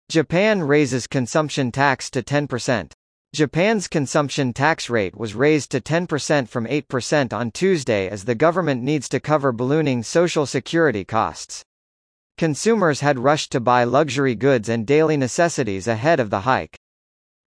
（区切りなしのナレーションです。）